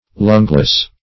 Lungless \Lung"less\, a. Being without lungs.